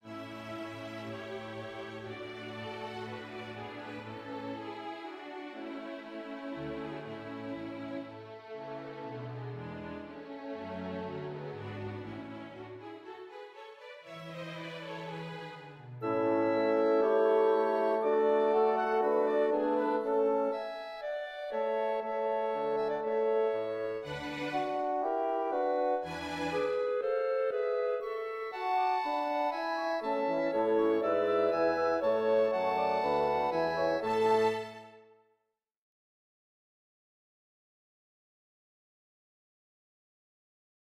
Le concerto pour piano et orchestre.
Le 23eme concerto, œuvre dans l’ensemble lumineuse démarre dans son premier mouvement en une forme sonate qui permet au compositeur d’exposer ses deux thèmes à l’orchestre avant de les reprendre au piano.